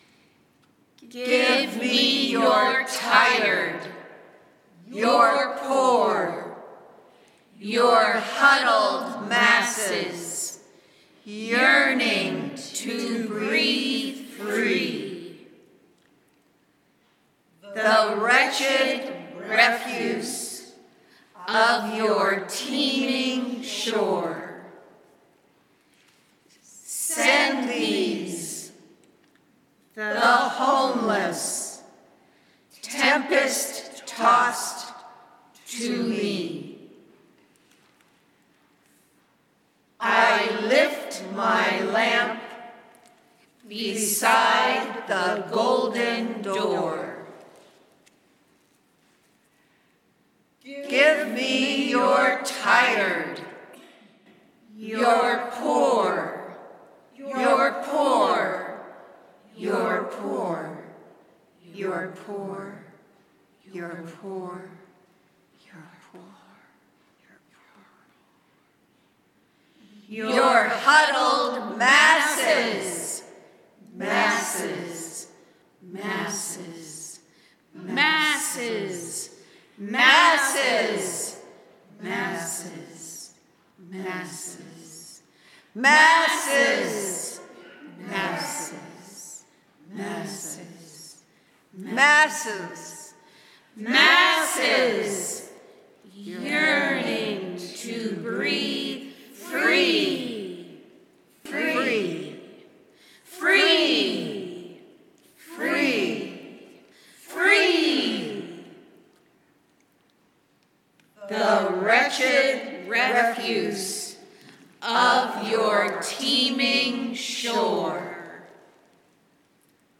in this interpretive reading that breaks open the suffering of the immigrant and our call as Christians and as U.S. citizens to respond to this suffering